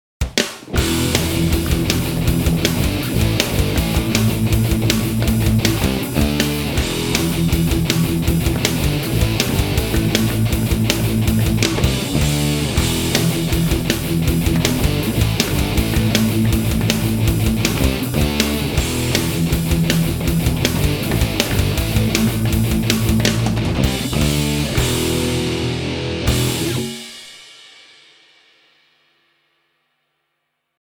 I came up with a simple rock riff and recorded it a total of four times – each amp first through a Shure SM57 microphone, then through its “rec. out/phones” jack. I used the “R-fier Stack” setting and left the EQ/tone knobs as flat as I could.
First up is the sound of the speakers as picked up by a Shure SM57. Micro Cube on the left, Cube 40GX on the right. Microphone was placed slightly off-center.
amp-test-microphone.mp3